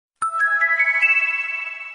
Message Tones